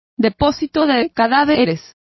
Complete with pronunciation of the translation of mortuary.